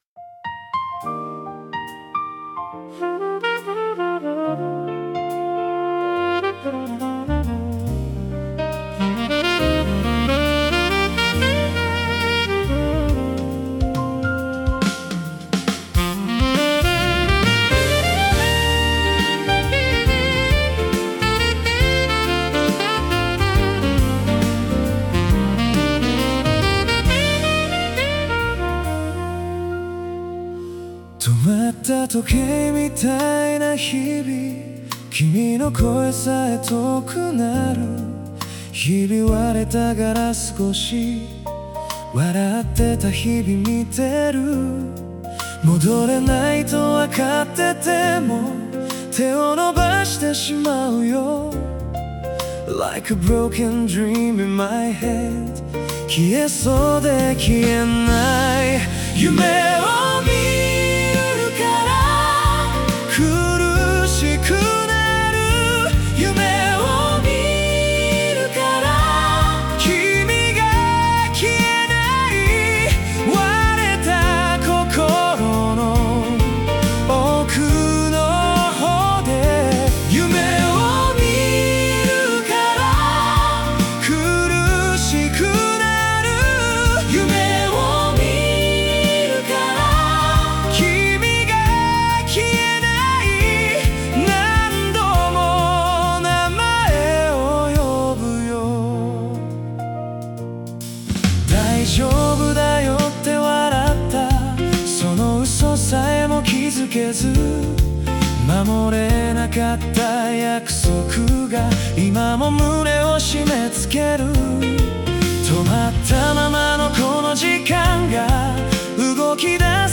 男性ボーカル